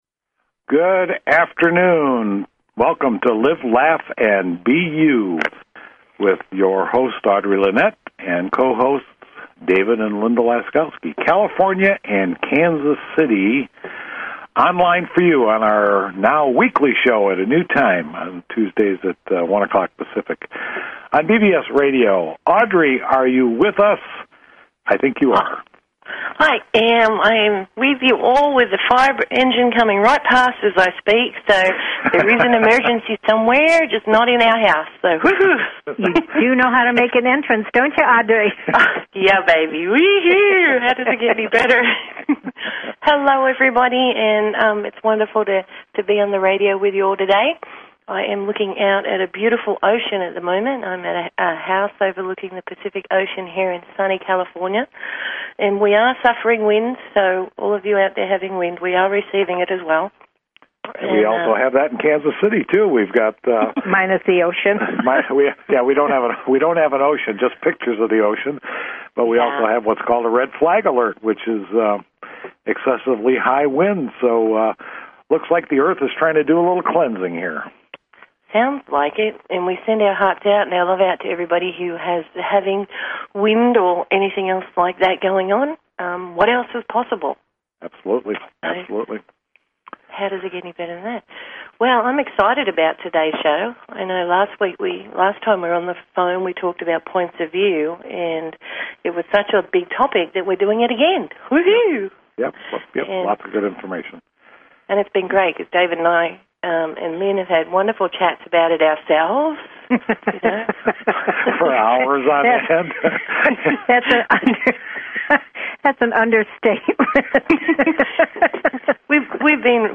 Talk Show Episode, Audio Podcast, Live_Laugh_and_BE_You and Courtesy of BBS Radio on , show guests , about , categorized as